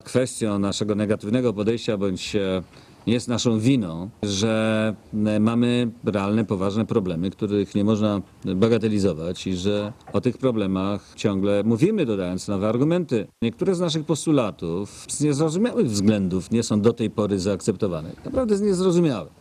Posłuchaj ministra Cimoszewicza
cimoszewicz_w_brukseli.mp3